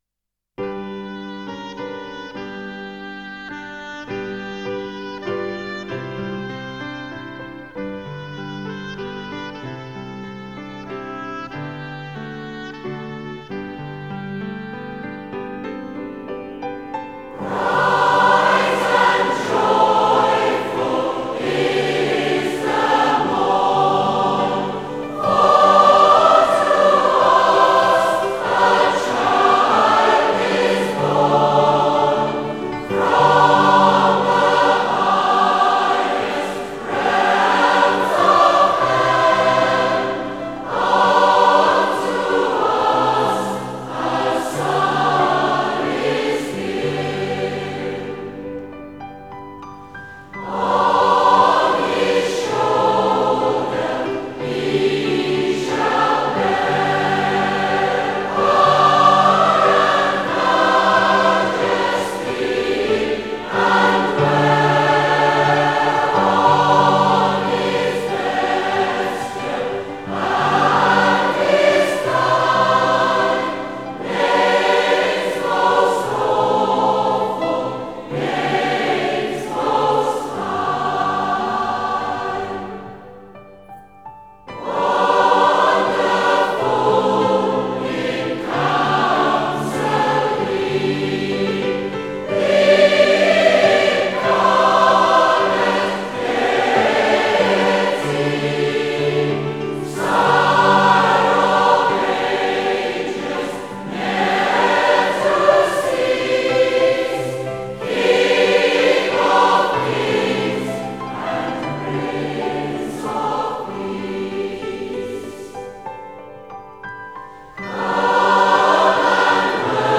赞美诗 | Bright and joyful is the morn 光明欢乐这清晨